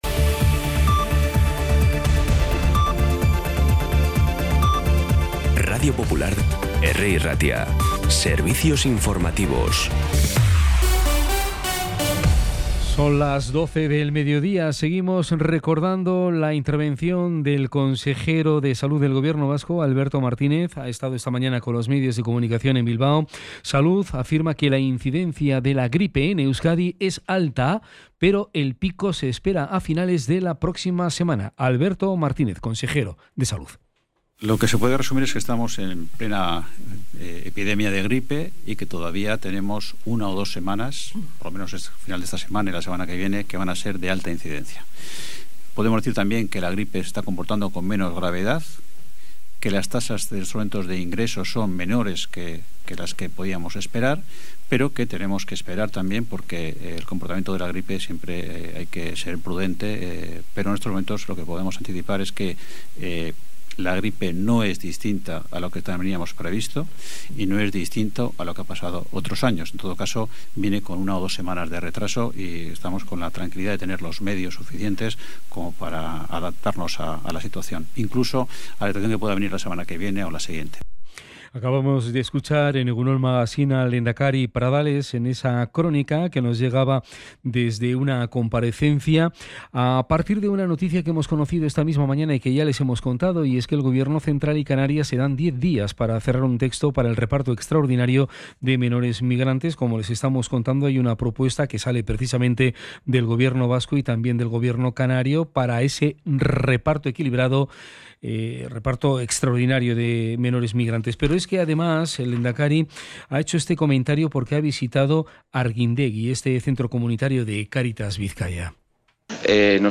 Las noticias de Bilbao y Bizkaia del 9 de enero a las 12
Los titulares actualizados con las voces del día.